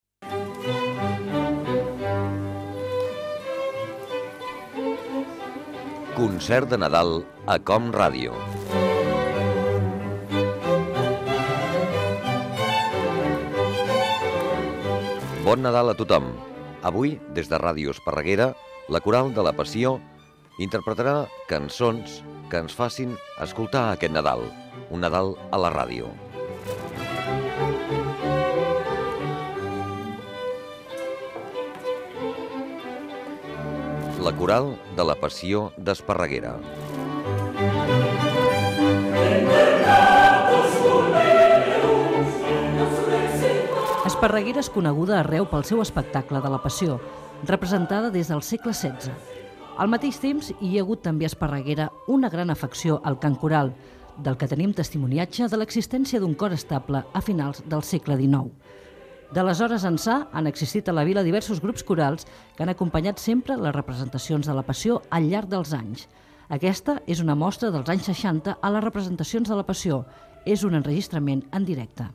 Presentació del concert amb cançons de Nadal, amb la Coral de la Passió d'Esparreguera.
Musical
FM